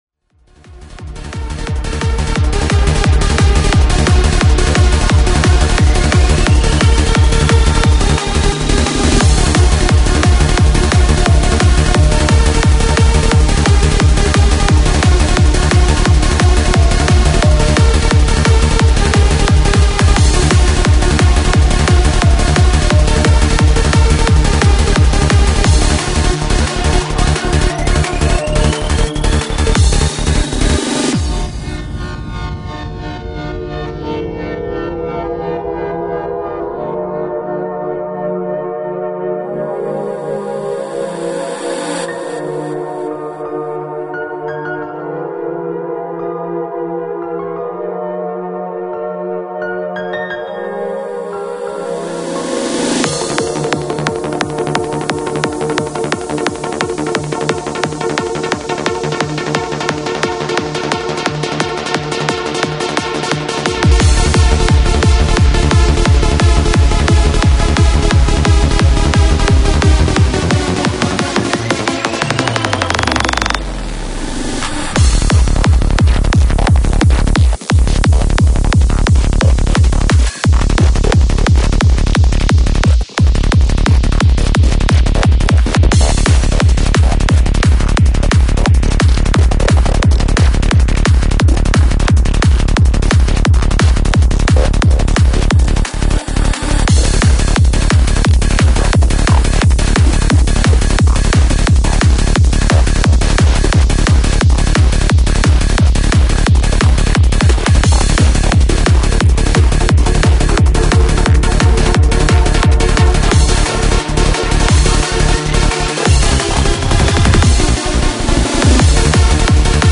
Japanese anthemic trance energy